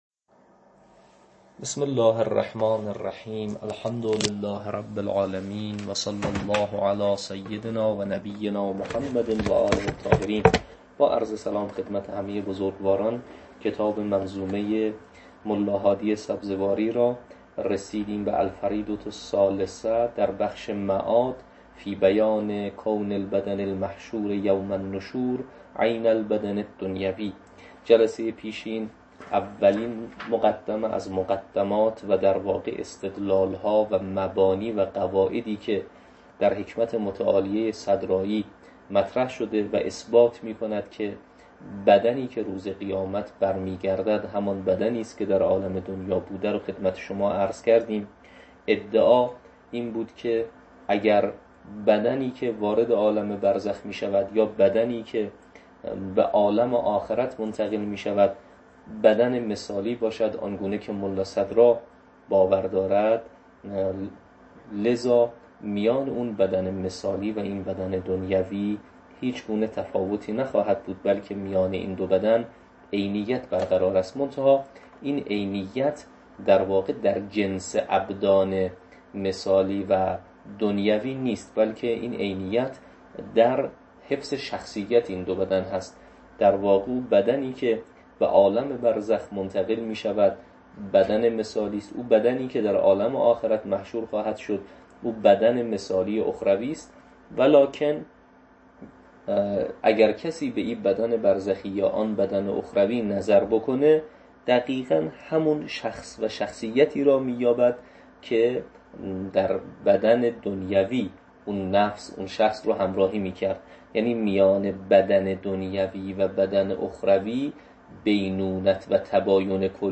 تدریس کتاب شرح منظومه